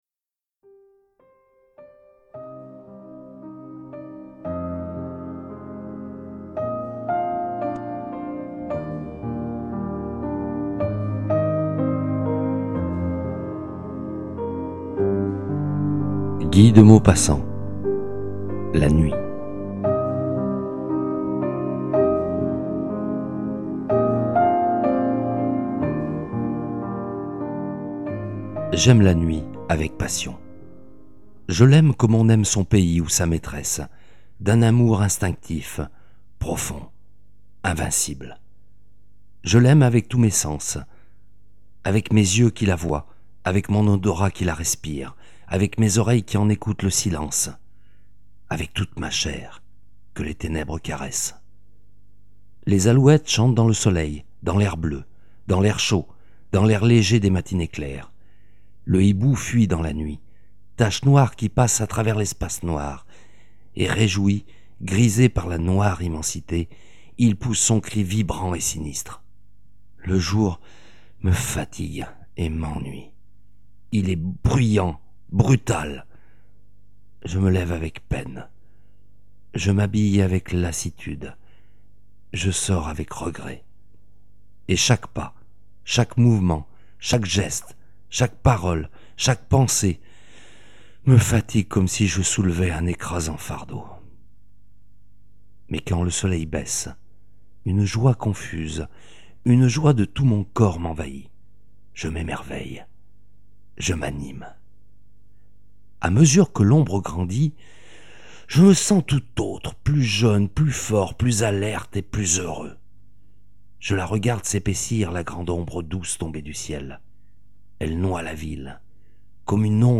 Références musicales : Musopen – Song from a secret garden.